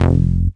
cch_bass_one_shot_shtwo_F.wav